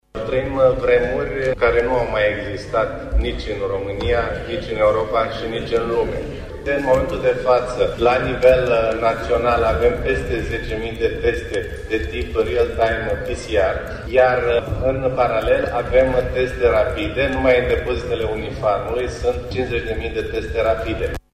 Ministrul Sănătăţii, Victor Costache, le-a spus ieri parlamentarilor că în prezent, în ţara noastră, există un stoc de 50.000 de teste în timp real şi rapide pentru depistarea virusului: